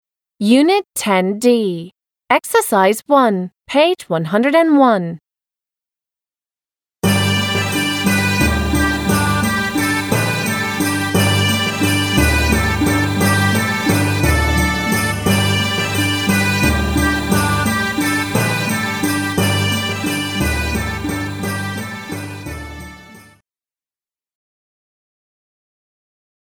Ответ: The music reminds me of Scotland.